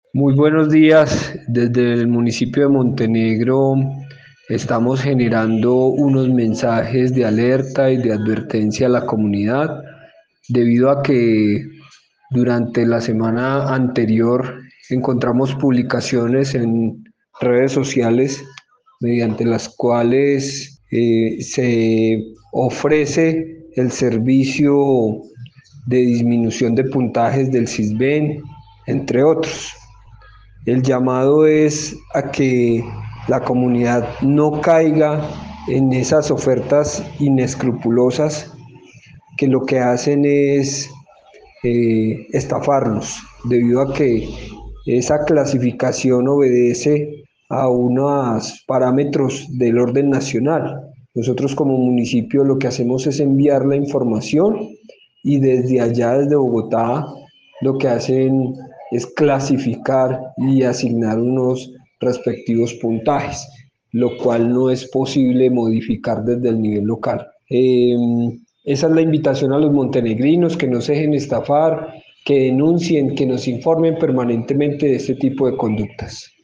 Secretario de Gobierno de Montenegro